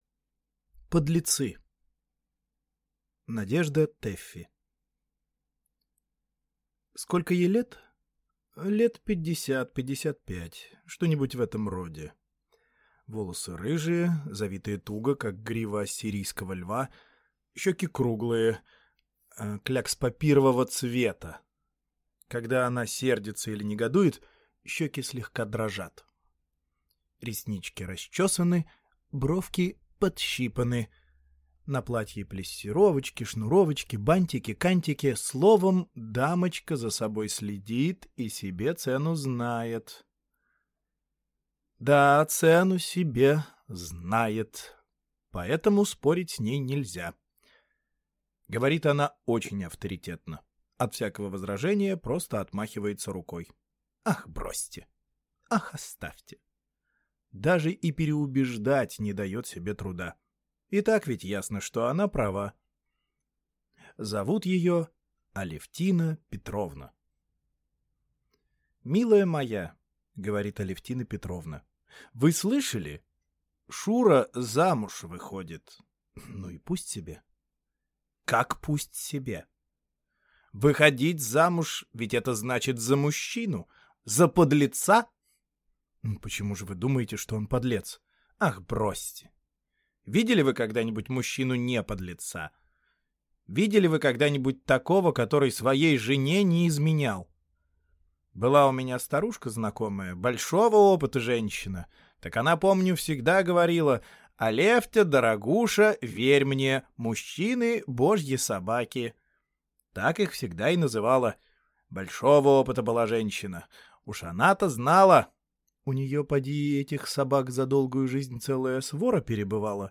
Аудиокнига Подлецы | Библиотека аудиокниг